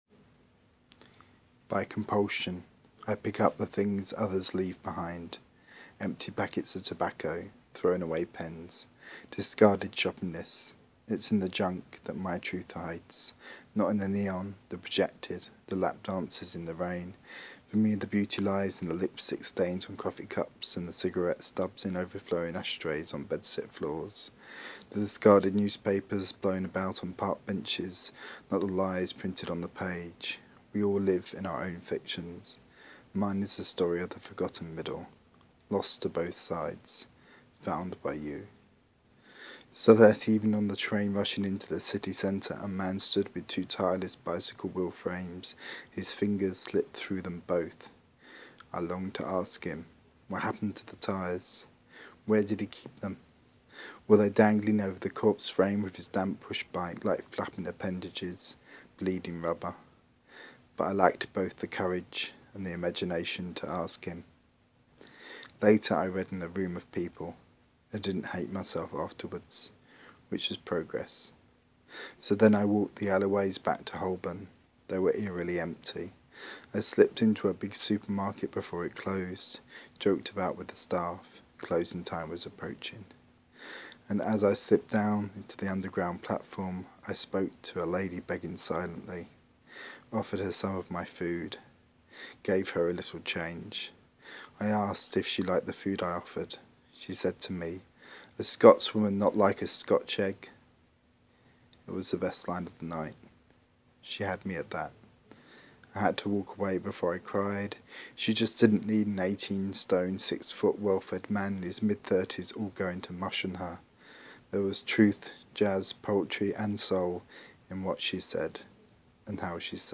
Spoken word